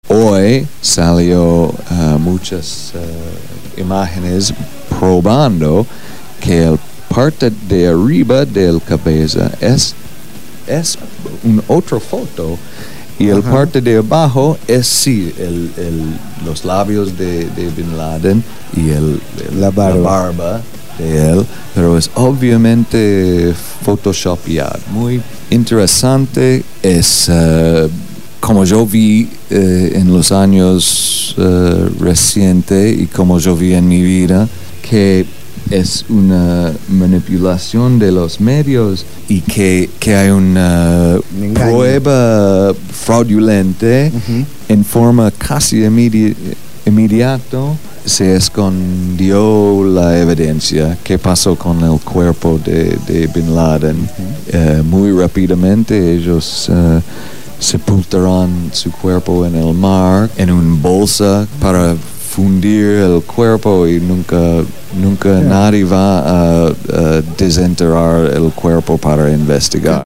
en los estudios de Radio Gráfica FM 89.3